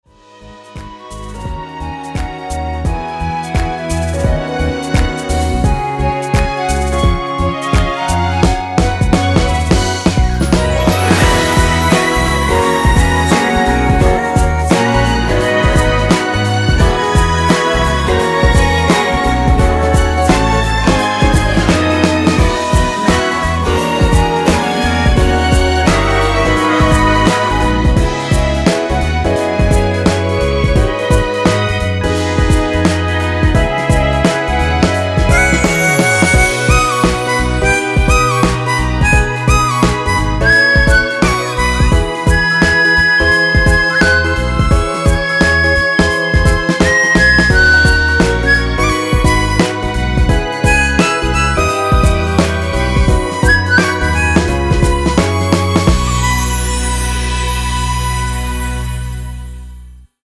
엔딩이 페이드 아웃이라서 노래하기 편하게 엔딩을 만들어 놓았으니 미리듣기 확인하여주세요!
원키에서(+3)올린 멜로디와 코러스 포함된 MR입니다.
앞부분30초, 뒷부분30초씩 편집해서 올려 드리고 있습니다.